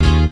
neptunesguitar8.wav